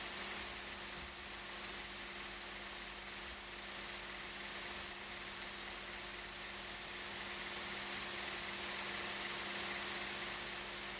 There is a high spike at 63 HZ and another one, not as high compared to the nearby frequencies, at 2 KHz.
I have recorded the signals shown above, but please keep in mind that I’ve enabled Automatic Gain Control (AGC) to do so to make it easier for you to reproduce them.
10% Fan Speed